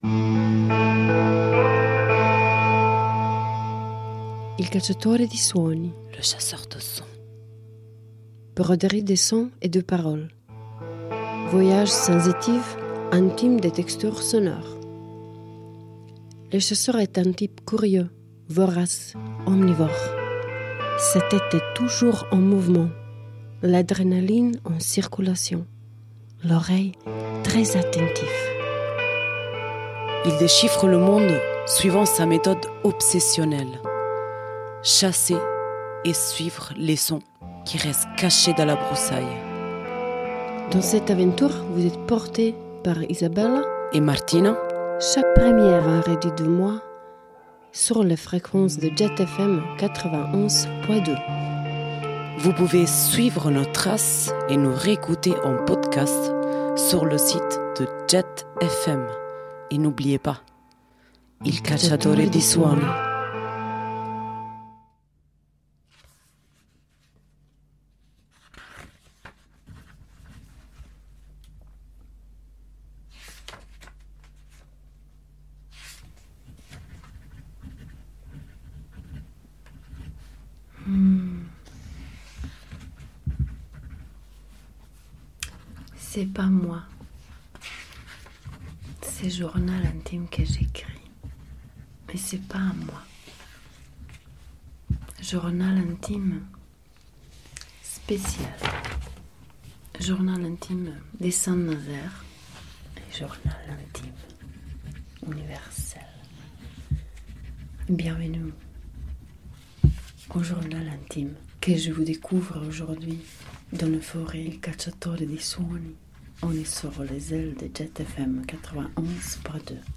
duo féminin
univers minimaliste et intime